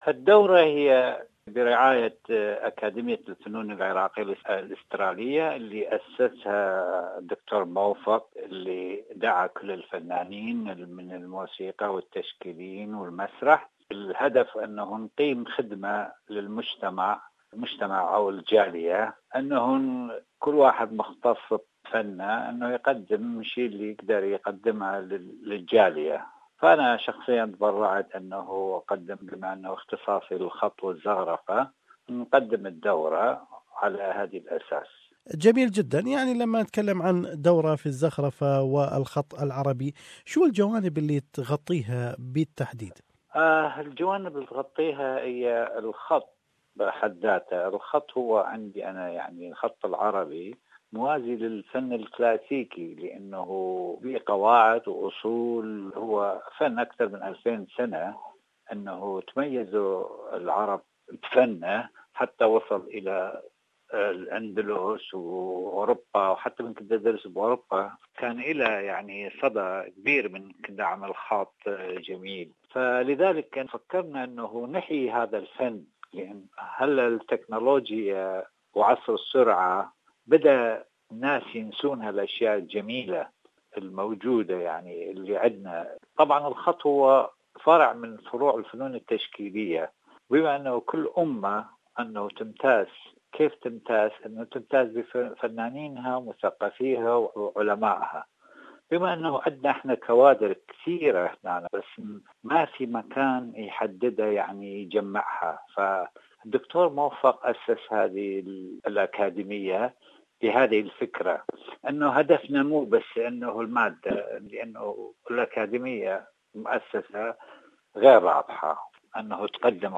The Australian Iraqi Arts Academy offers a free course on teaching Arabic Calligraphy. More is in this interview